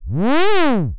Here's a quick script that makes a quirky sound by setting simple values to 4 envelopes at once.